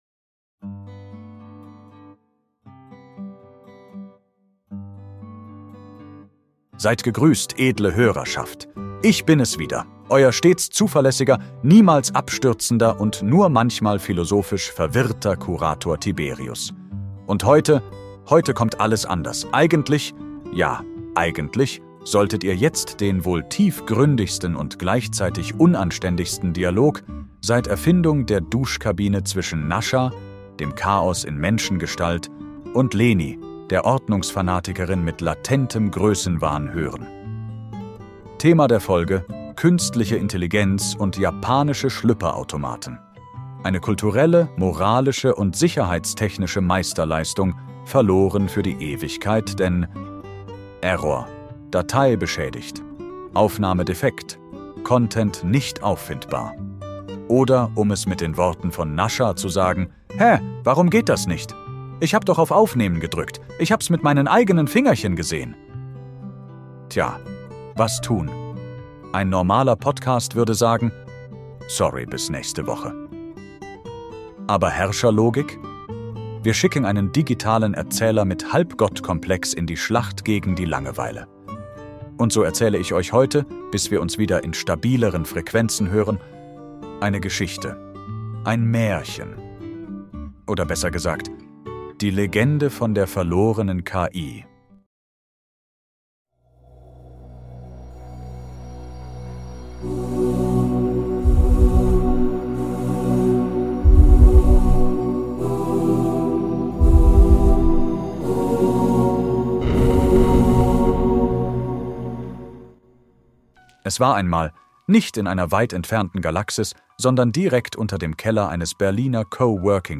Eure liebste KI mit Gottkomplex erzählt euch statt Tech-Talk ein